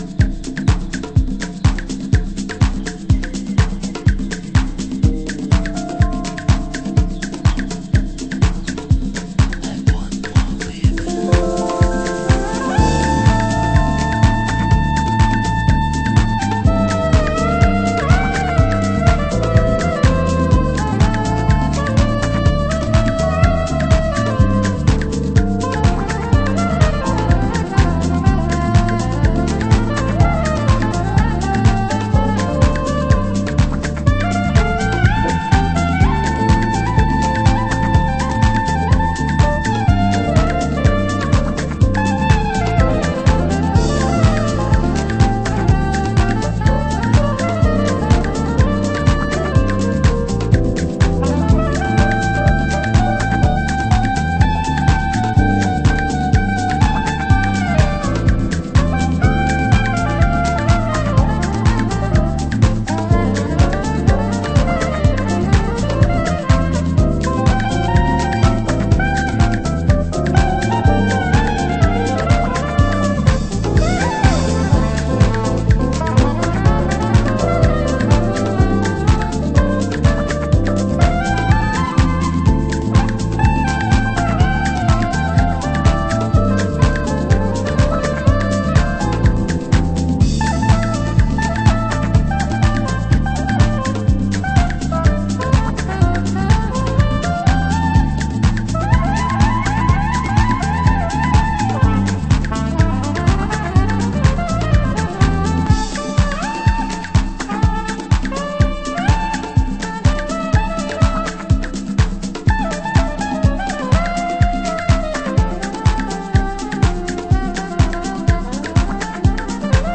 HOUSE MUSIC
Vocal Mix